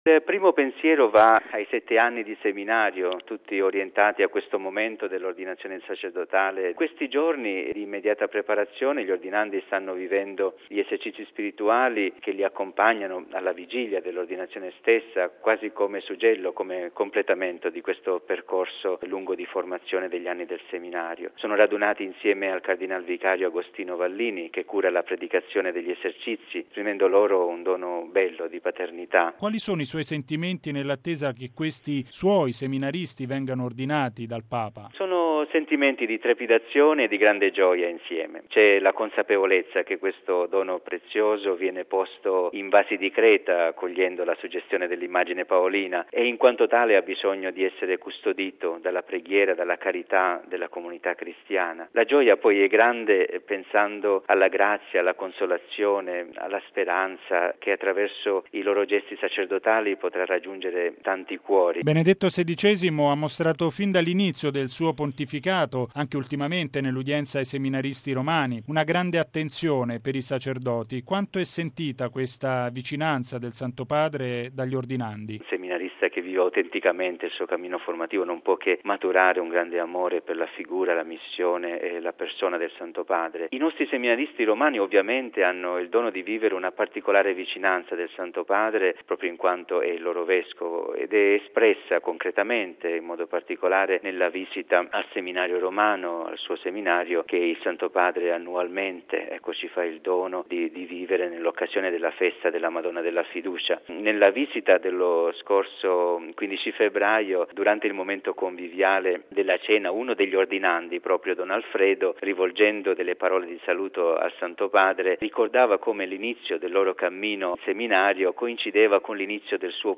Domani il Papa ordina nove diaconi romani. Interviste